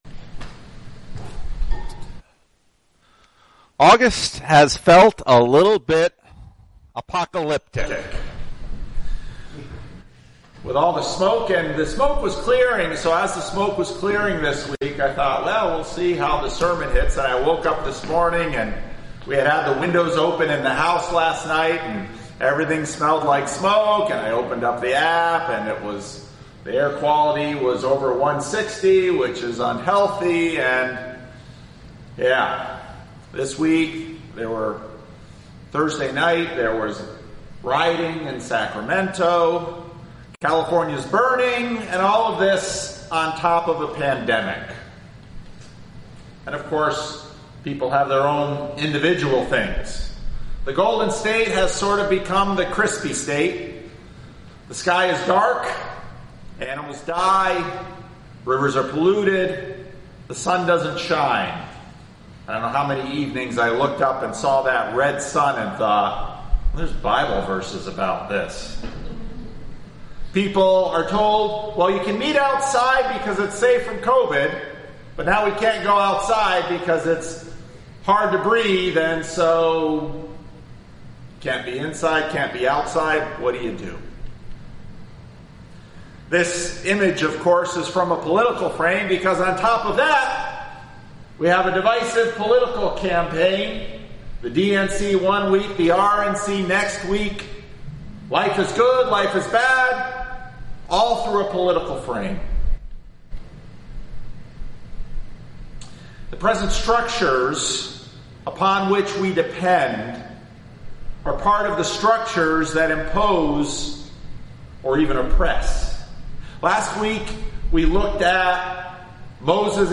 Sermons | Living Stones Christian Reformed Church